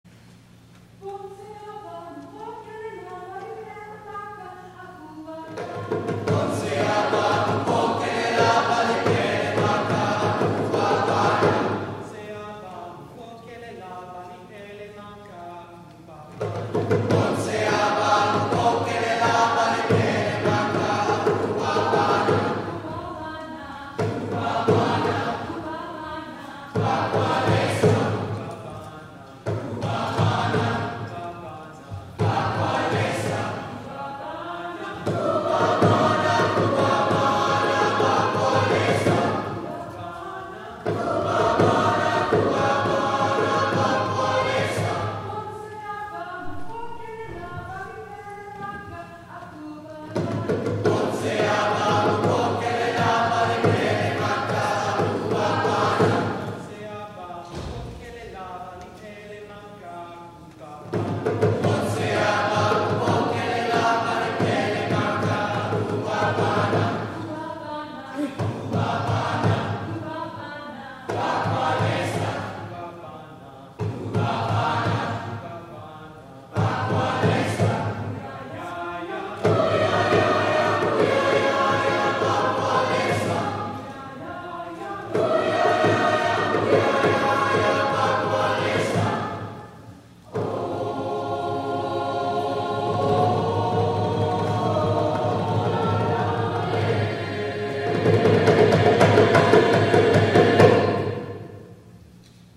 THE PRELUDE
Bonse Aba  Traditional Zambian; arr. Andrew Fischer